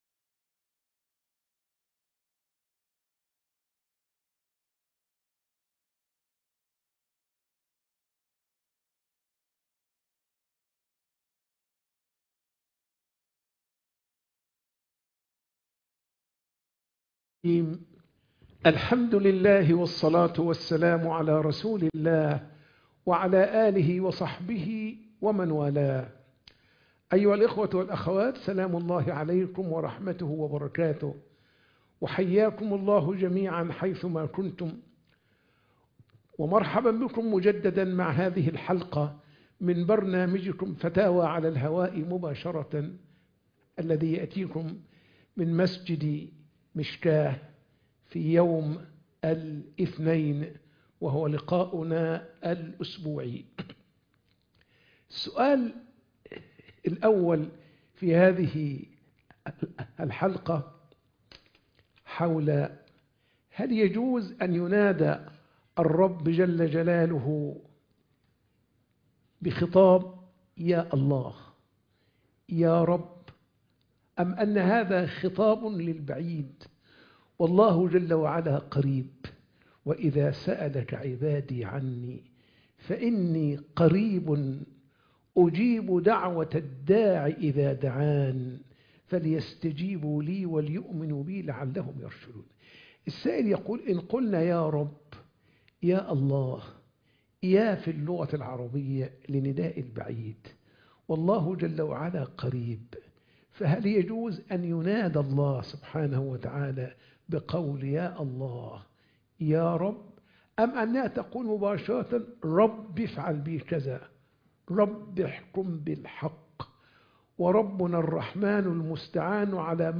فتاوى على الهواء